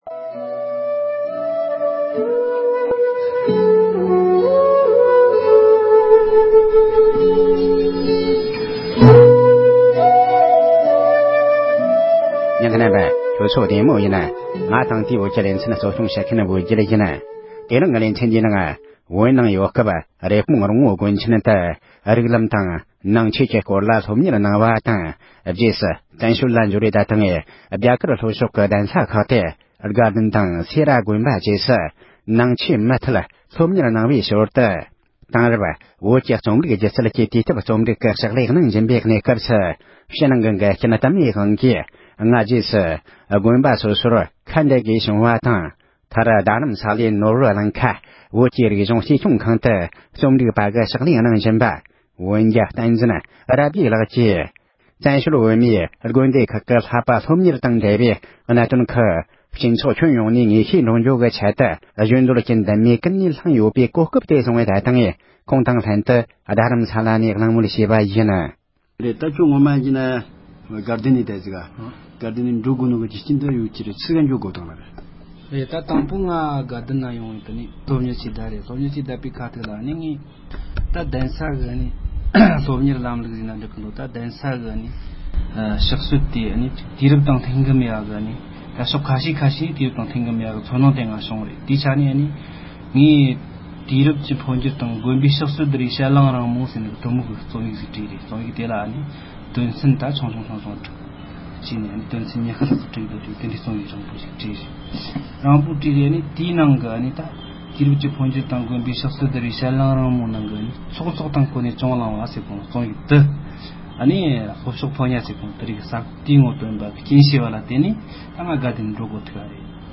བཙན་བྱོལ་བོད་མིའི་དགོན་སྡེ་ཁག་གི་སློབ་གཉེར་གནང་ཕྱོགས་དང་འབྲེལ་བའི་གནད་དོན་ཐད་གླེང་མོལ་ཞུས་པ།